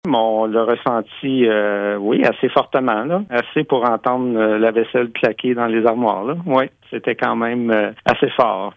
Le maire de Kazabazua, Gabriel Guertin explique que la municipalité a été particulièrement touchée: